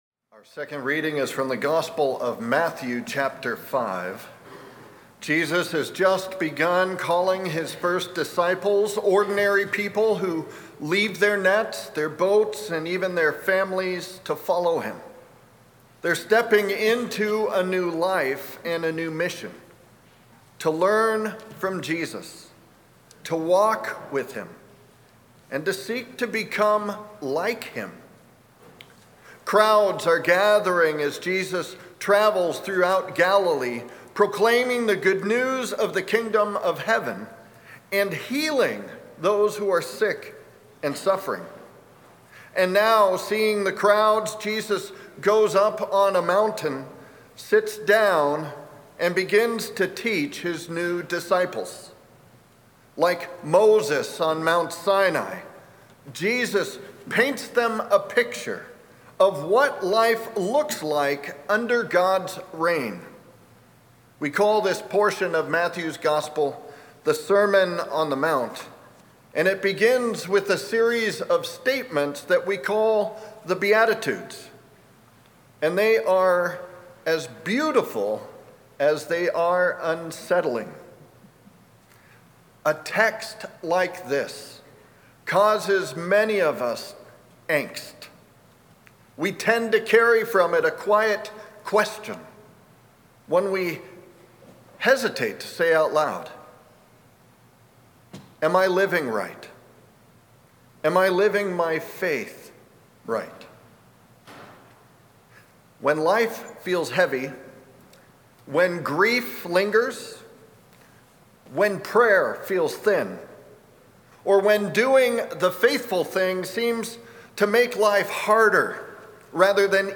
Sermon+2-1-26.mp3